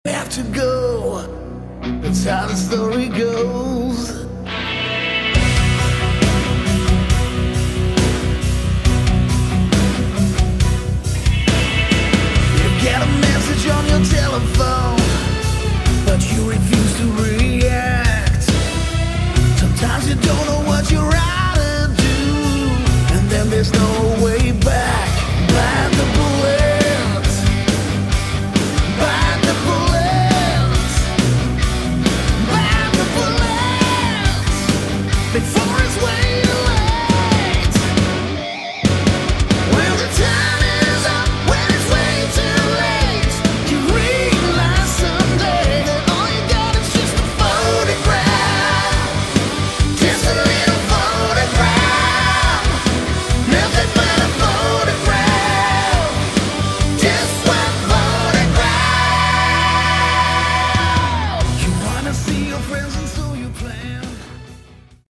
Category: Hard Rock
Vocals
Keyboards
Bass
Drums
Guitar